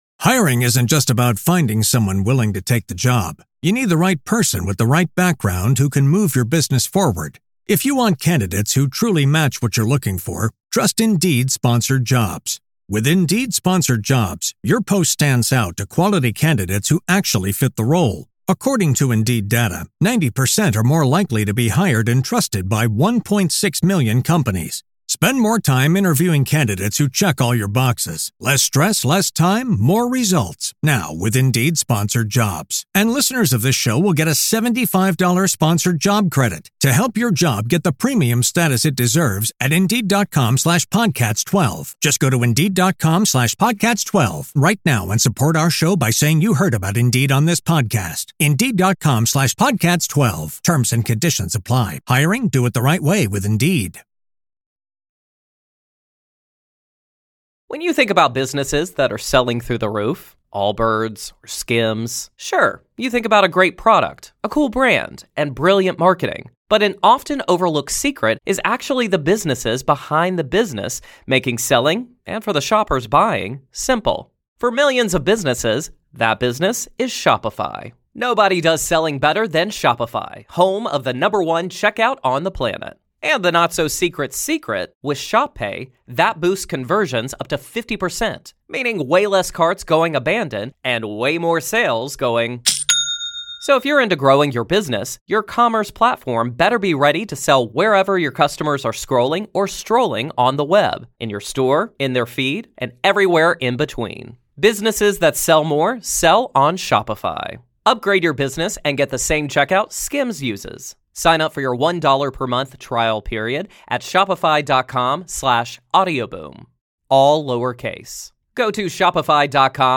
Speaker: Eric Thomas